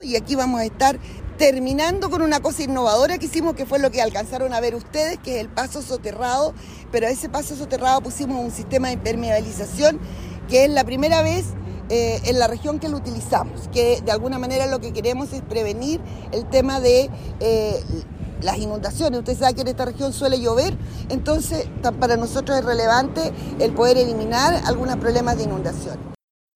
Si bien se habilitará el paso bajo nivel, los trabajos se mantendrán porque el contrato con la empresa a cargo es hasta mayo, recordó la directora regional del Serviu, María Luz Gajardo.